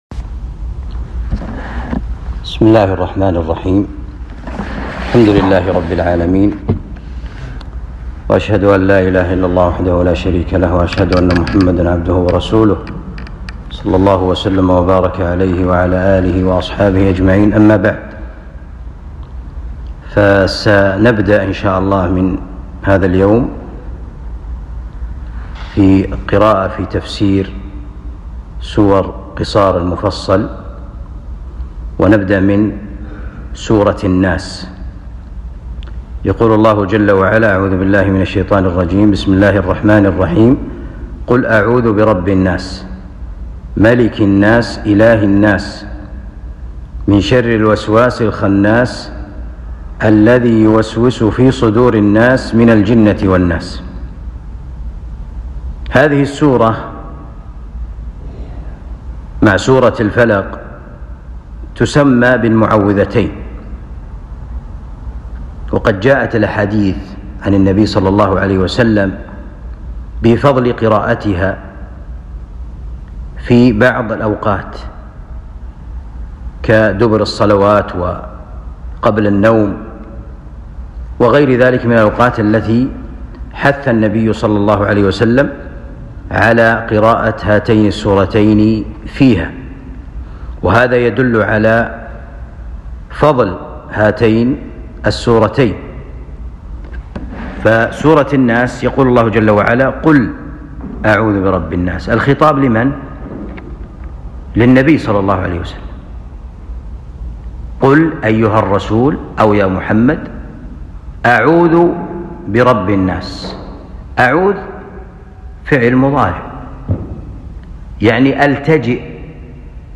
الدرس الأول من تفسير سور قصار المفصل (سورة الناس) الدروس التفسير المقطع 01.